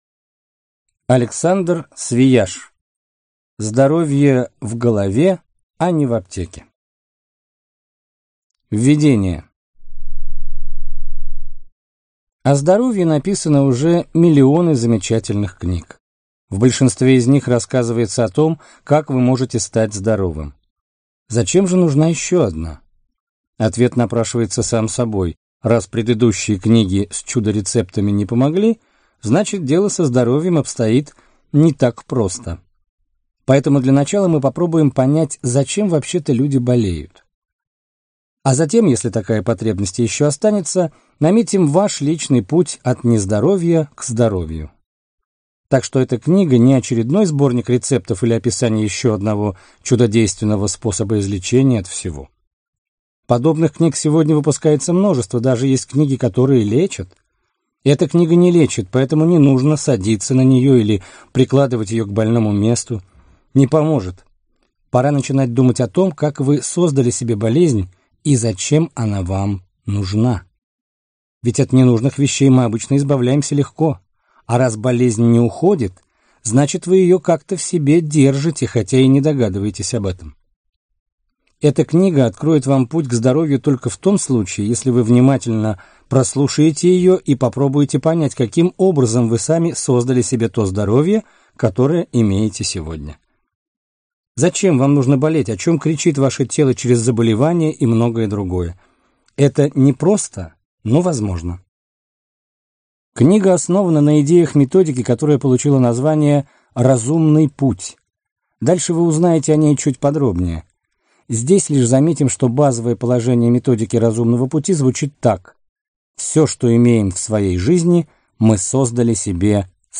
Аудиокнига Здоровье в голове, а не в аптеке | Библиотека аудиокниг